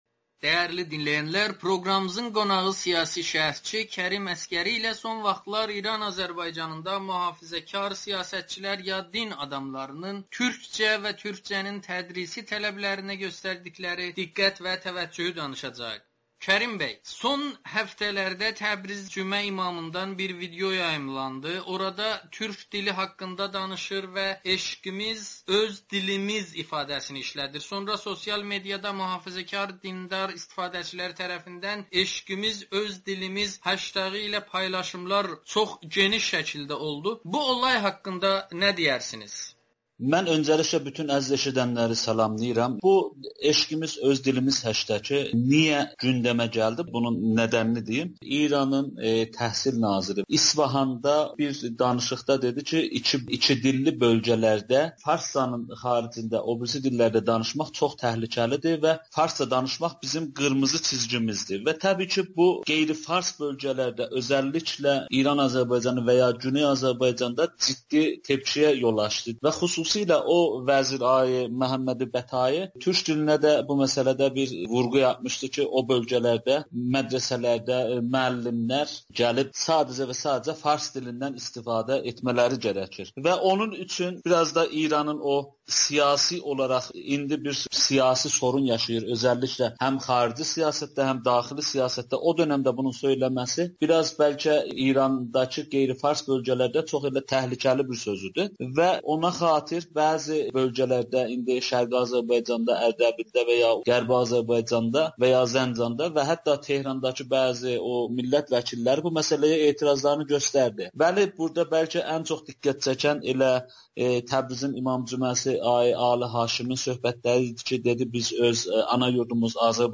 ‘Eşqimiz öz dilimiz’ deyən Təbriz cümə imamının məqsədi nədir? [Audio-Müsahibə]